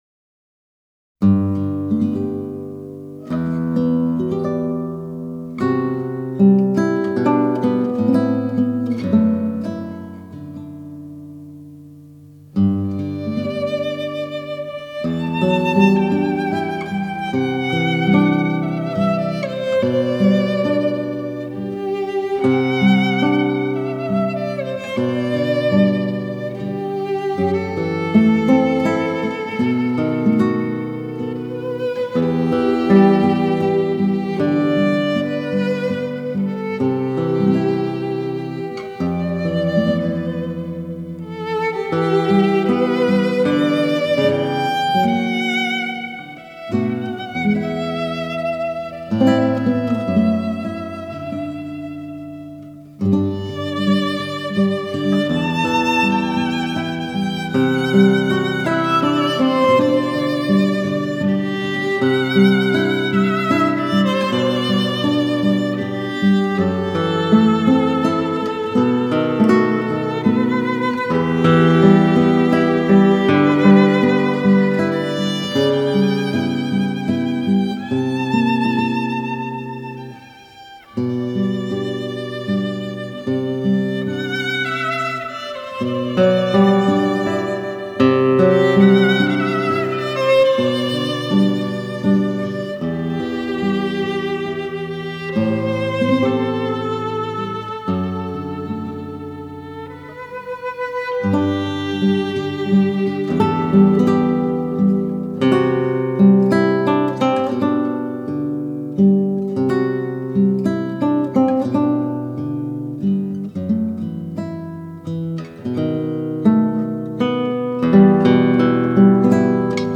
纯音乐